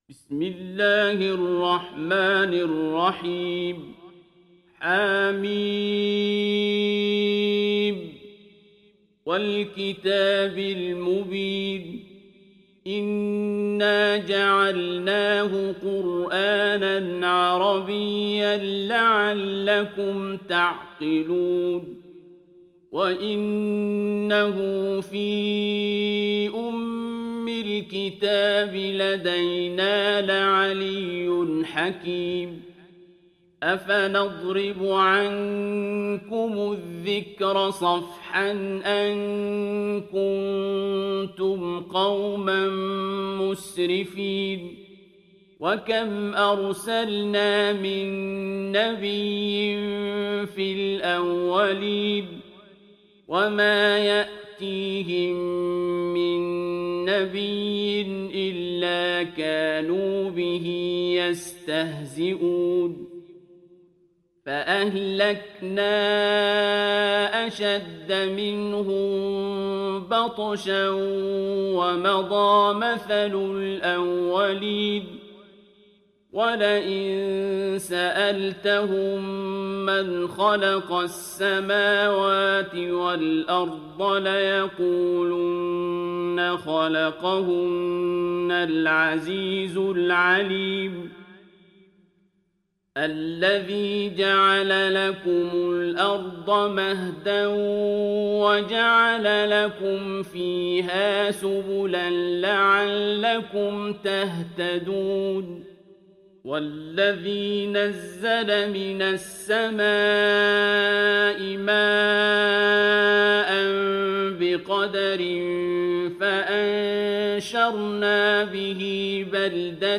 Zuhruf Suresi İndir mp3 Abdul Basit Abd Alsamad Riwayat Hafs an Asim, Kurani indirin ve mp3 tam doğrudan bağlantılar dinle
İndir Zuhruf Suresi Abdul Basit Abd Alsamad